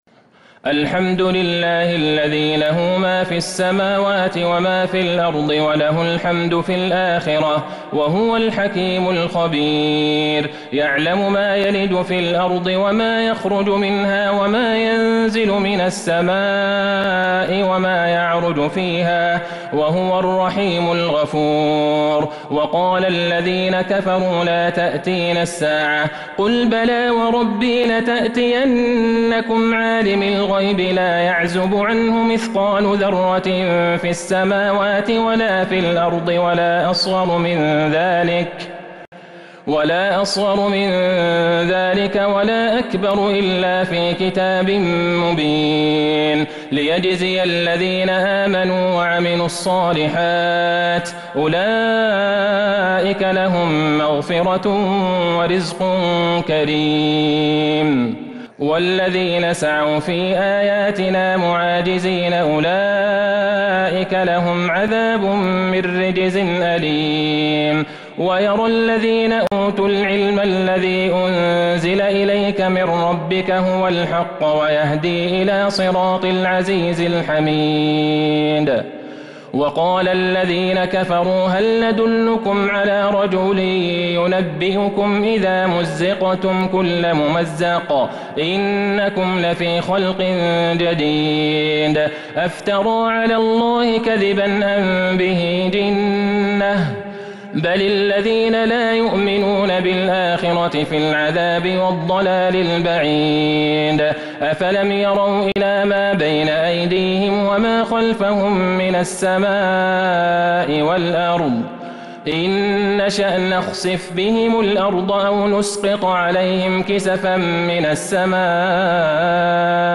سورة سبأ كاملة من تراويح الحرم النبوي 1442هـ > مصحف تراويح الحرم النبوي عام 1442هـ > المصحف - تلاوات الحرمين